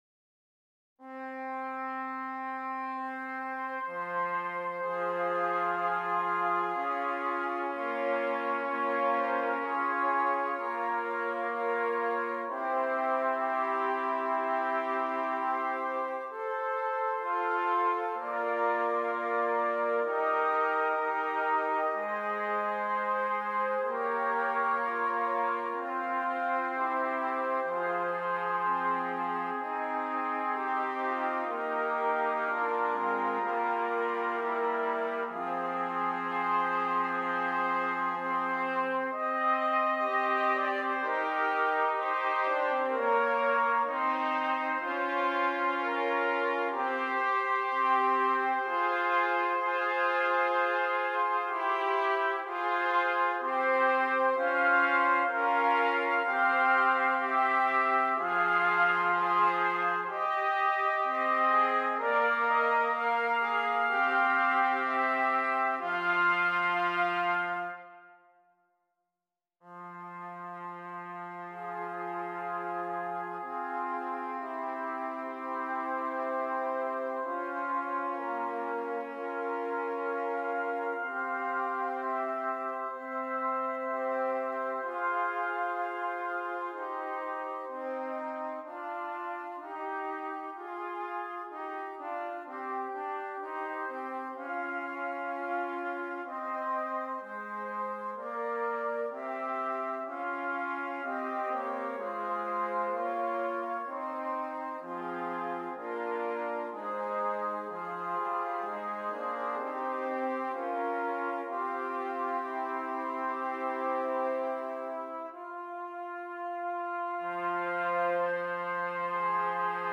4 Trumpets
arranged here for 4 trumpets.